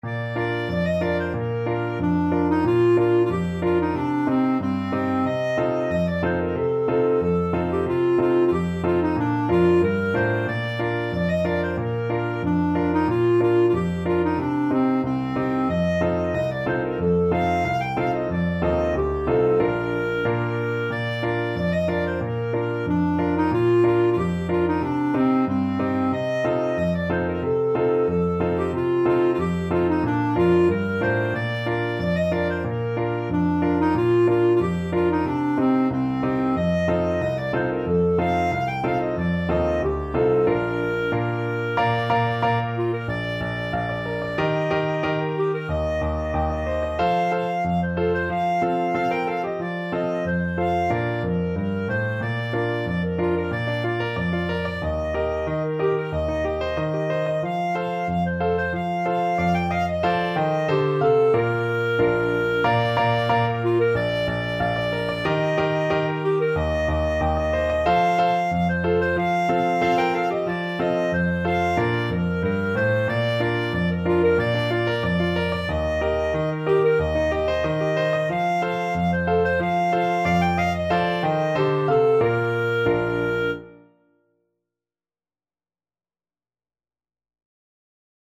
Traditional Music of unknown author.
2/4 (View more 2/4 Music)
Moderato =c.92
World (View more World Clarinet Music)
Danish